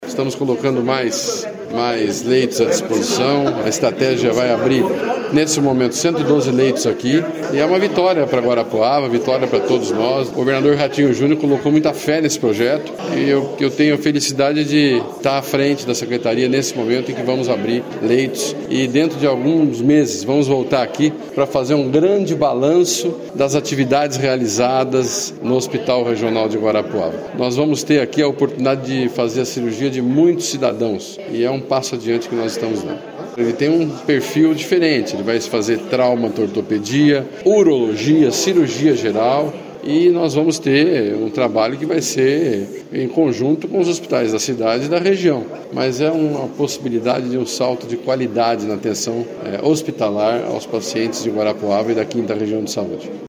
Sonora do secretário da Saúde, Beto Preto, sobre a abertura de novos leitos no Hospital Regional de Guarapuava